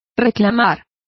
Complete with pronunciation of the translation of protest.